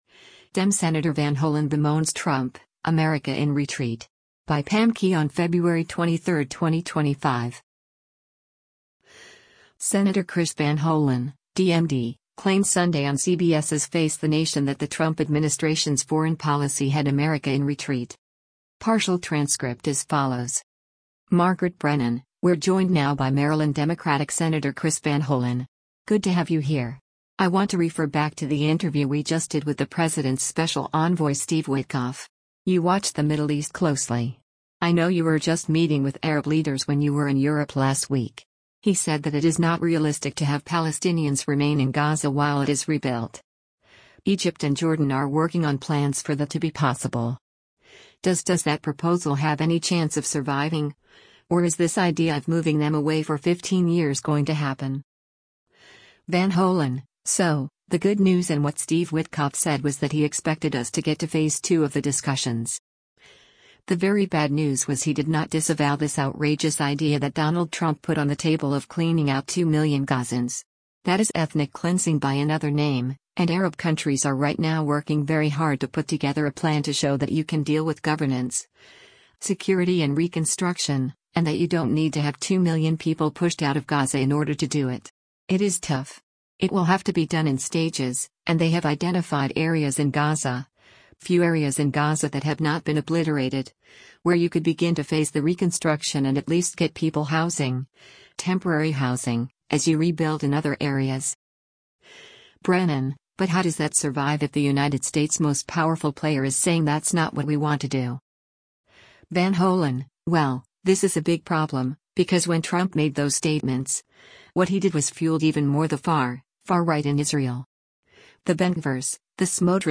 Senator Chris Van Hollen (D-MD) claimed Sunday on CBS’s “Face the Nation” that the Trump administration’s foreign policy had “America in retreat.”